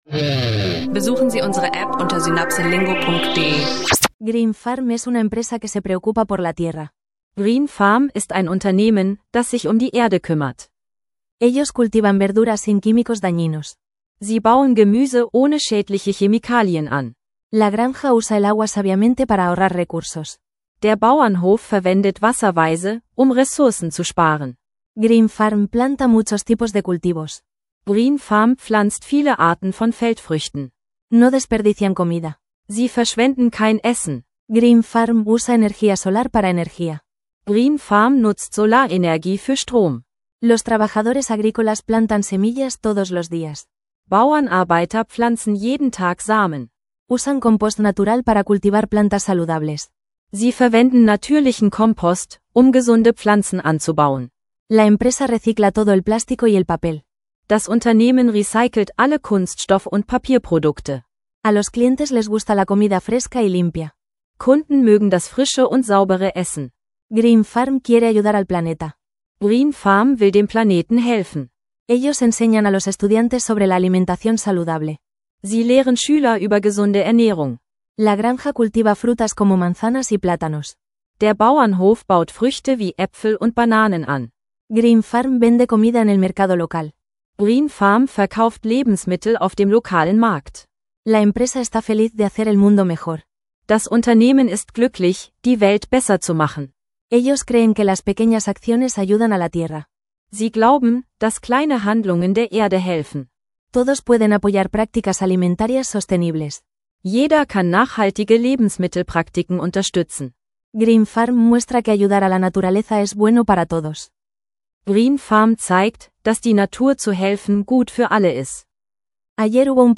In dieser Folge lernst du Spanisch mit praktischen Audiolektionen rund um nachhaltige Ernährung, Fußball und kulturelle Themen. Unser SynapseLingo Spanisch Sprachkurs bietet dir interaktives Spanisch lernen leicht gemacht, ideal für Anfänger und Fortgeschrittene.